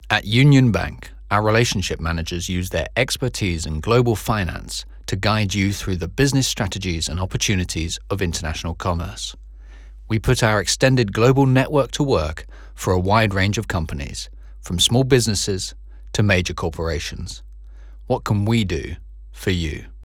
• Main Voice Reel